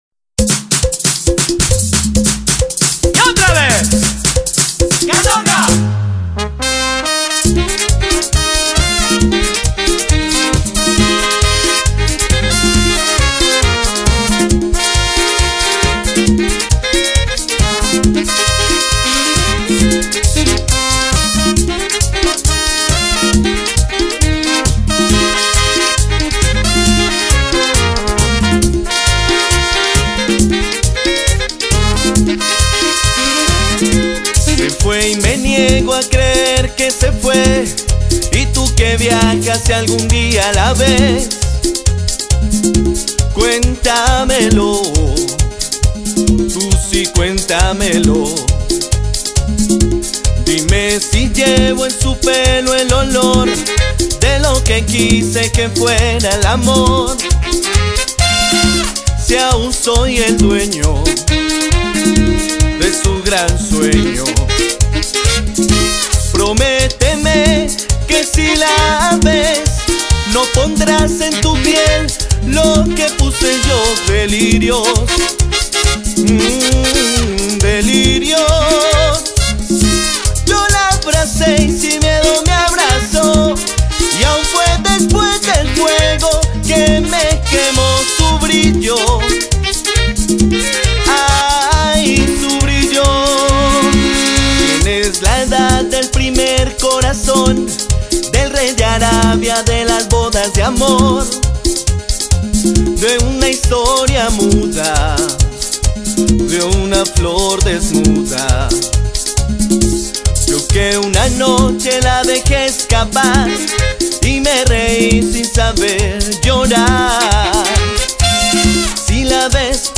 una orquesta colombiana